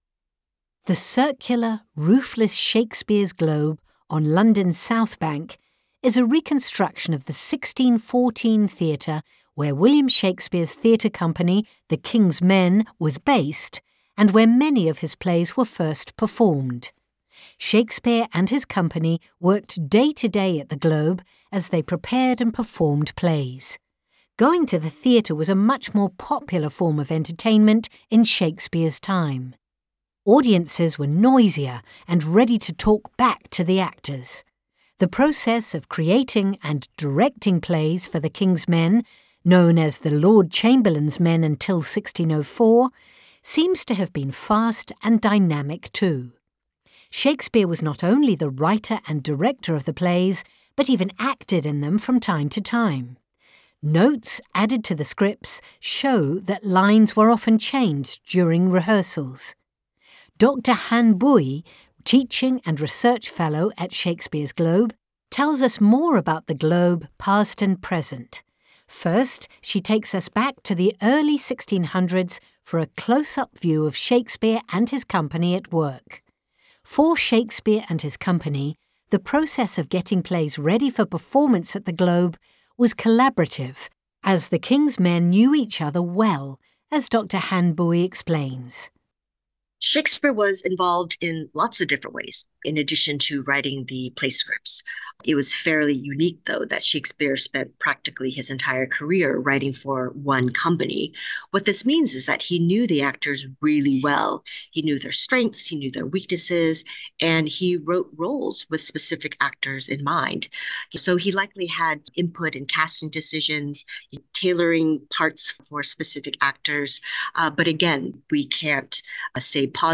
American accent